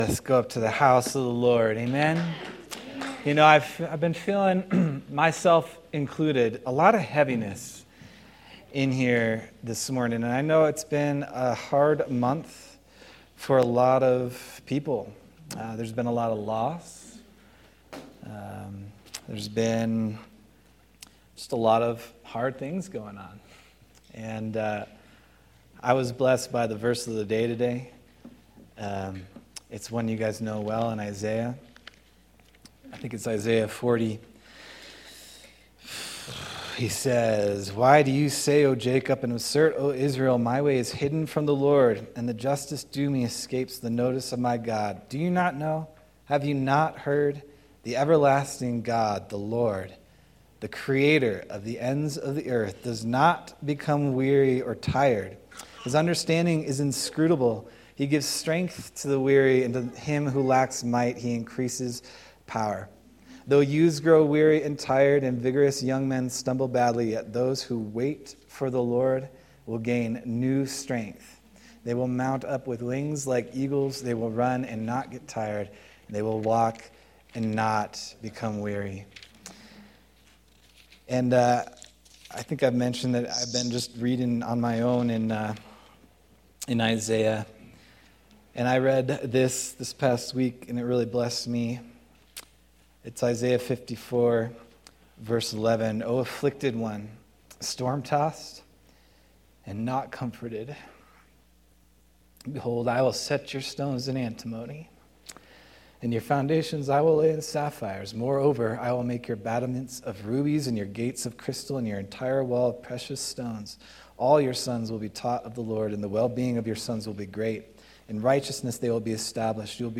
August 24th, 2025 Sermon